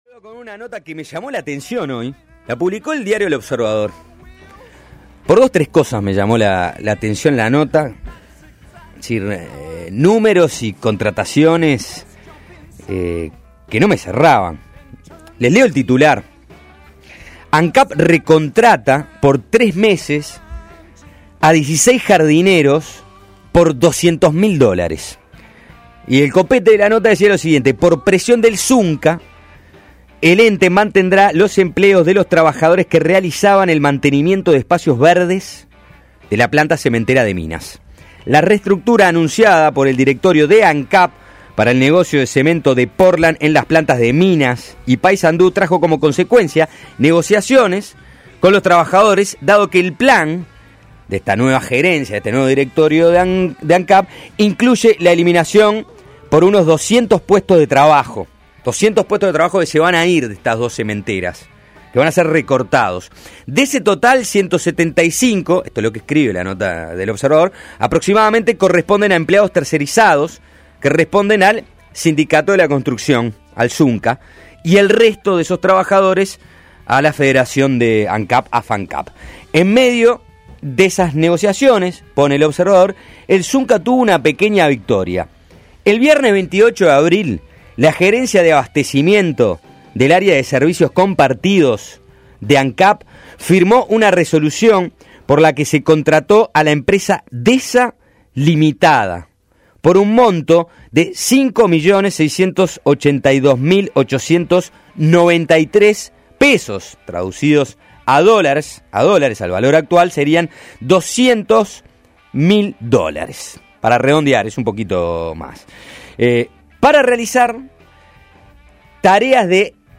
Entrevistado en Suena Tremendo